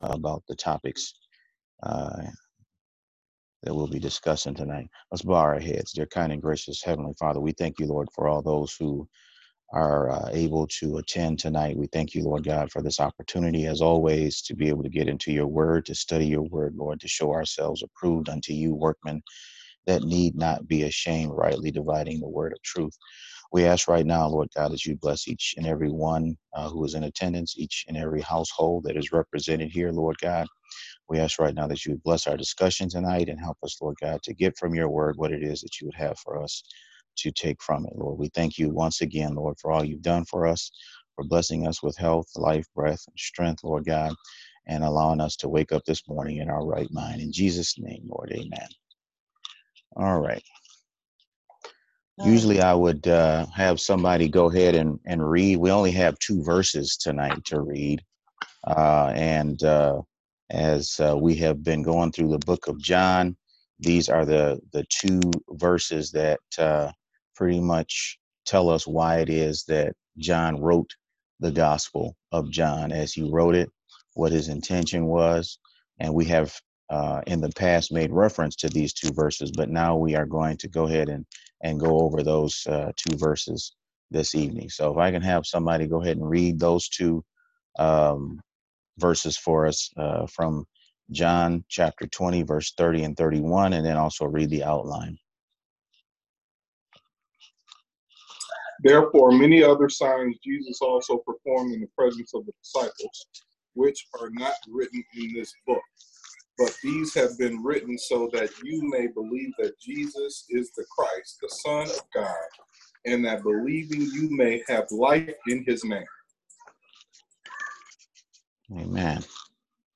Bible Study - New Life Community Church